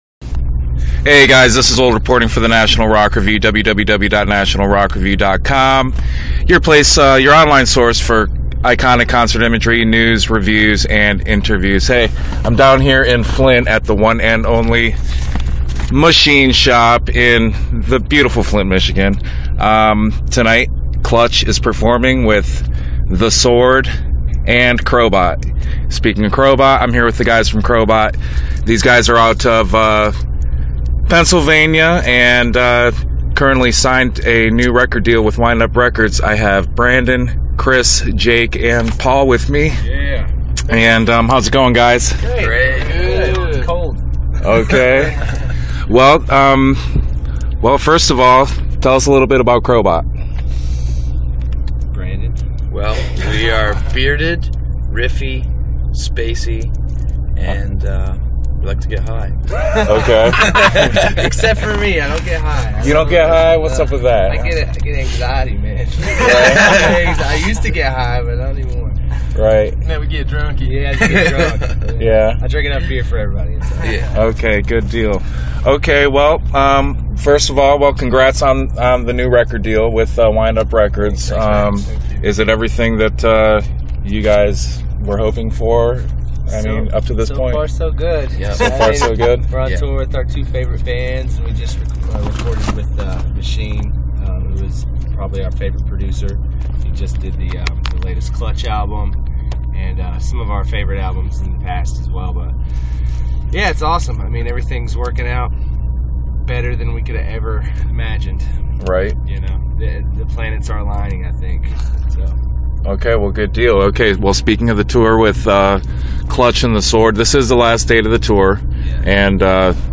Interview: Crobot Is Awesome / You Must Buy Their CD Now - National Rock Review
The guys took a few moments to chat with the National Rock Review before the final date of their current tour over at The Machine Shop in Flint, Michigan.